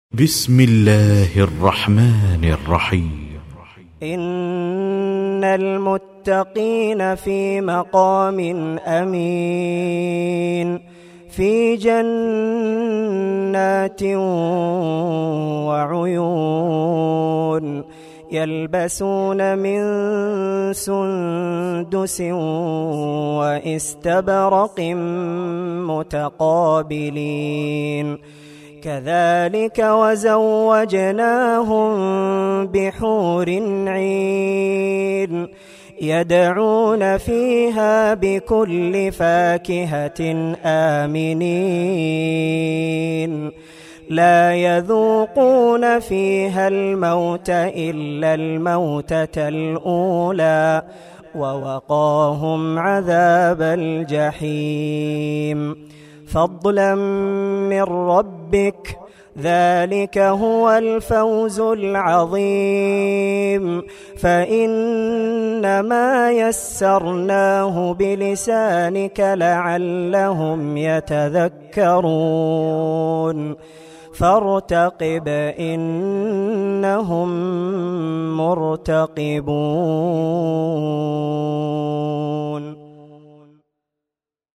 الرئيسية تلاوات خاشعة ماتيسر من سورة الدخان
القرآن الكريم